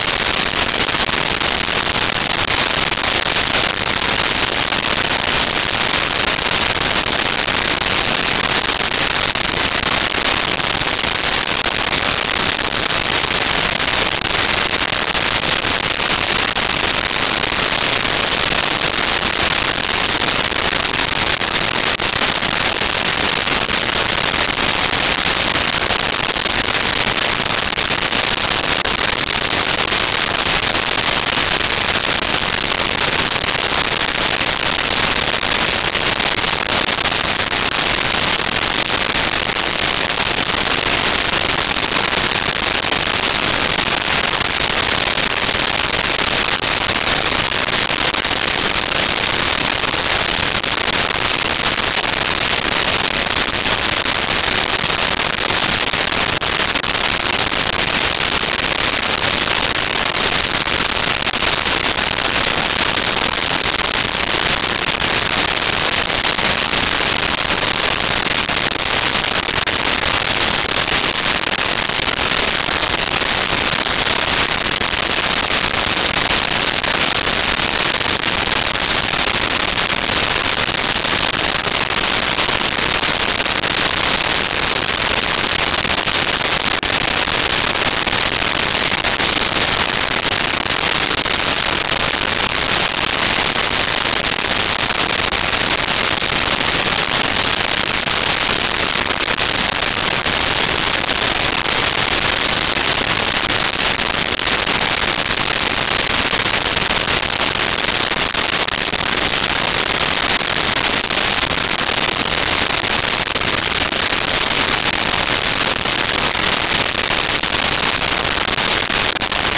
Laundry Interior
Laundry Interior.wav